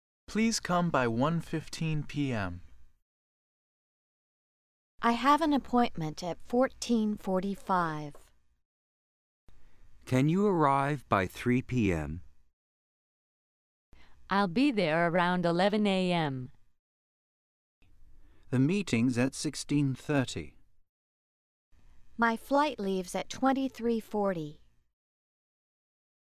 Listen to the audio with people talking about the time and take notes of the hours you hear.